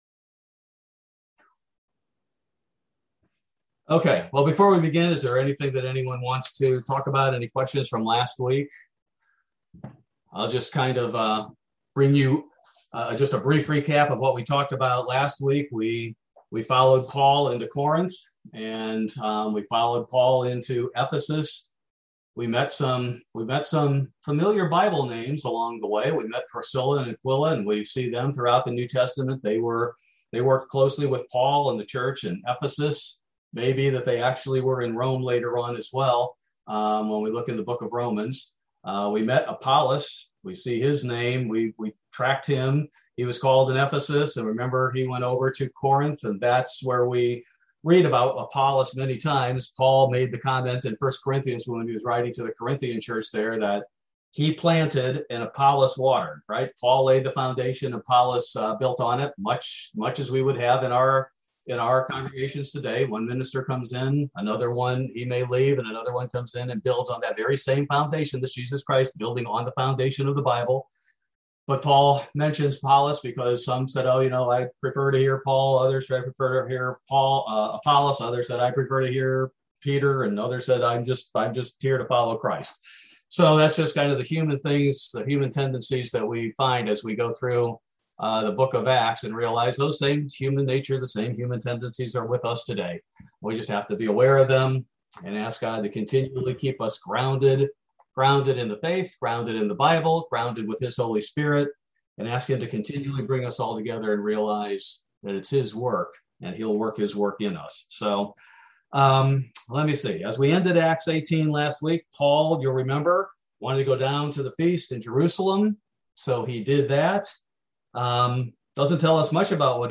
Bible Study: November 17, 2021